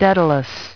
Транскрипция и произношение слова "daedalus" в британском и американском вариантах.